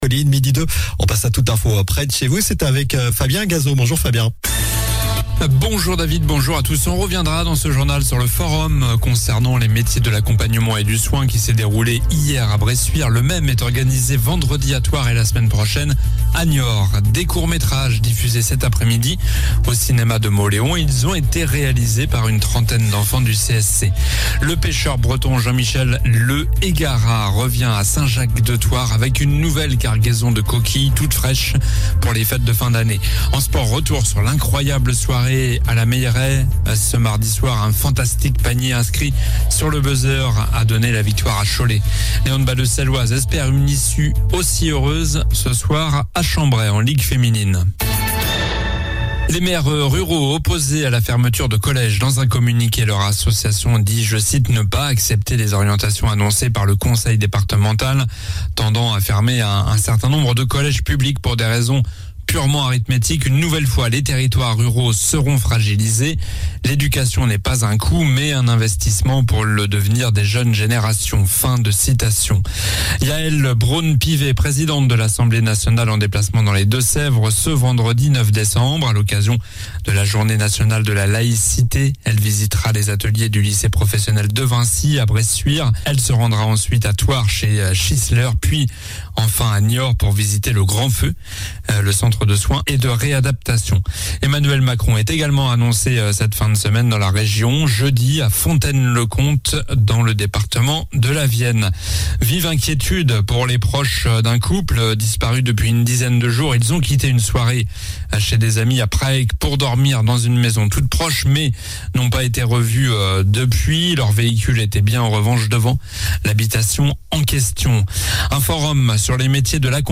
Journal du mercredi 7 décembre (midi)